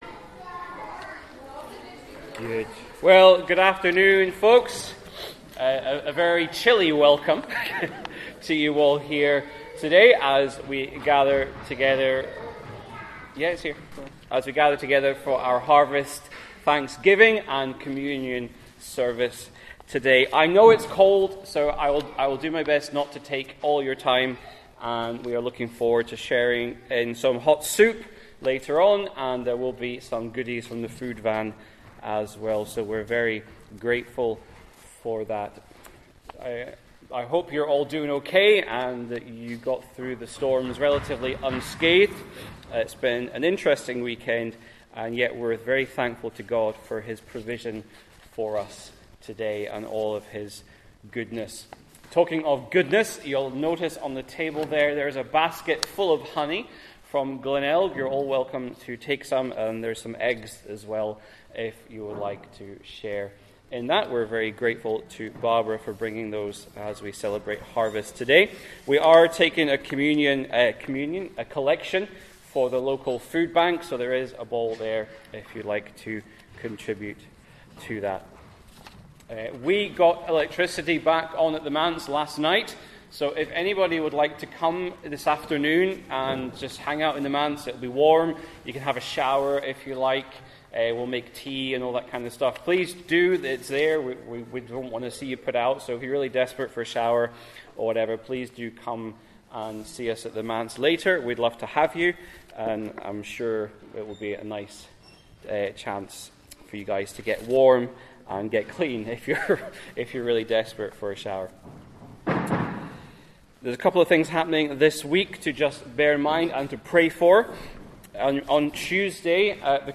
Harverst-Service-.mp3